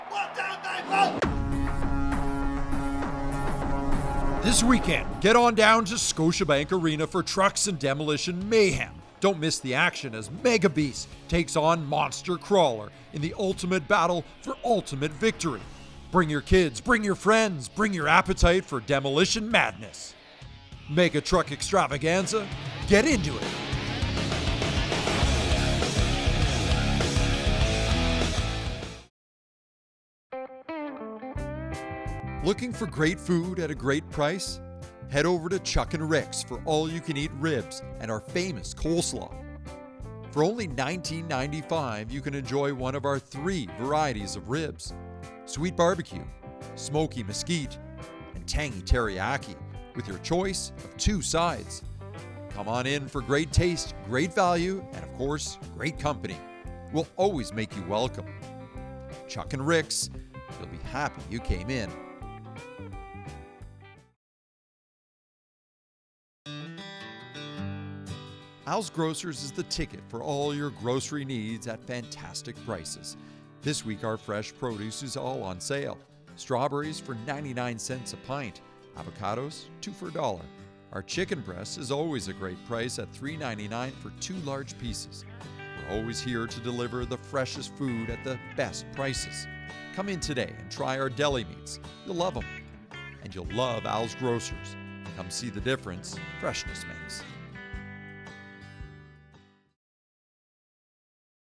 Commercial Voice-Overs
commercial_voice-overs.mp3